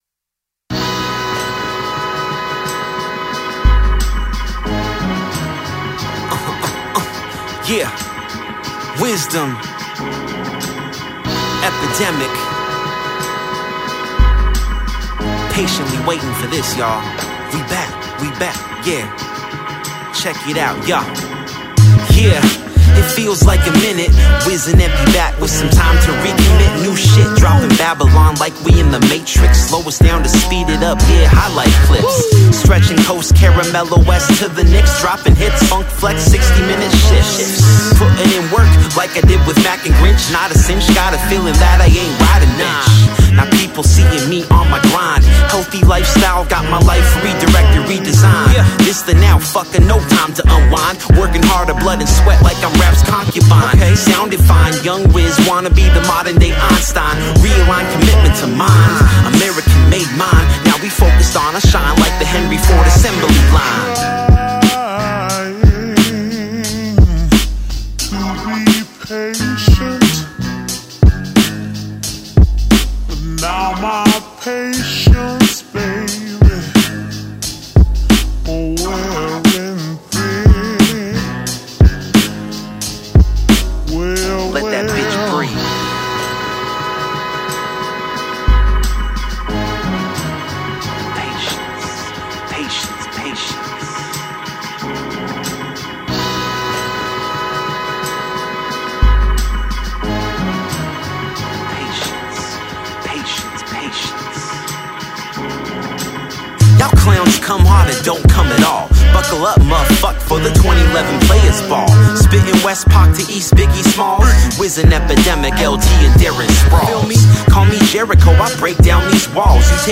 A worthy slapper for your Thursday.